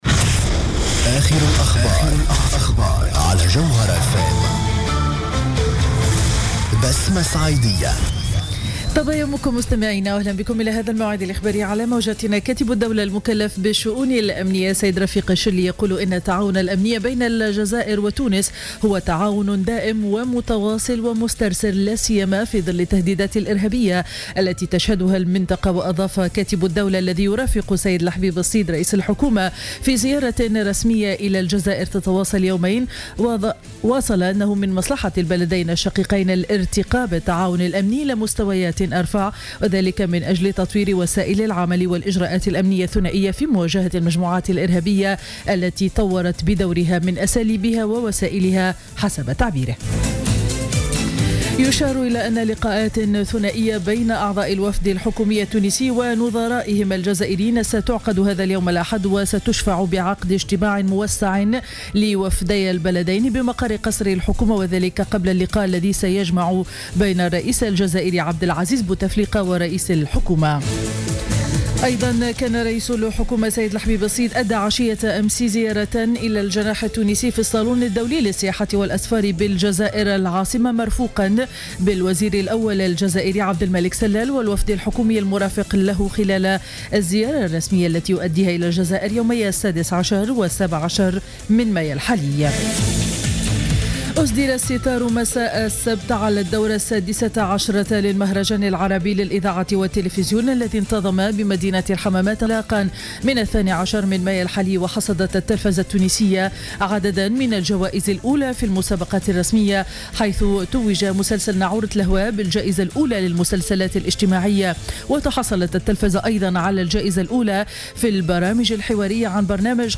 نشرة أخبار السابعة صباحا ليوم الأحد 17 ماي 2015